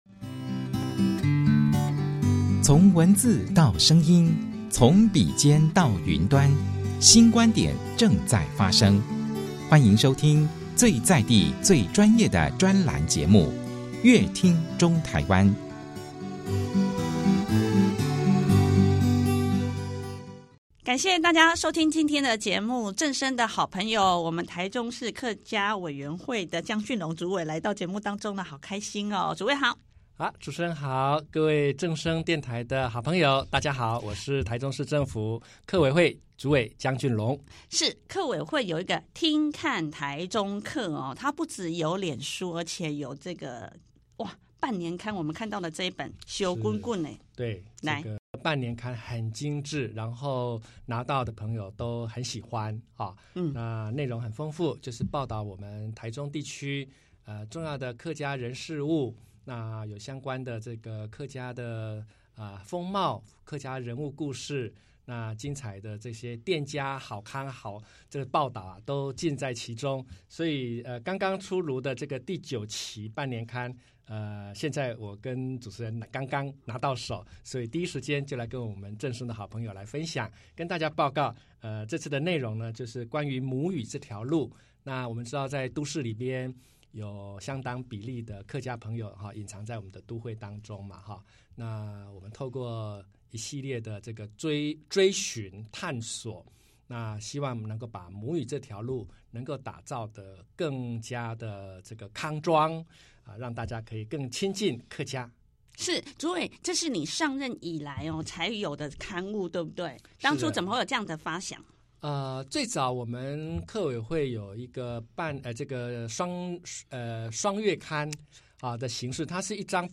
《聽．看臺中客》半年刊精彩呈現 《聽．看臺中客》2024半年刊出刊了，江俊龍主委透過節目專訪，娓娓道出母語務實落地的百種可能，在半年刊裡，可以感受到客家語言與文化綿延的溫暖。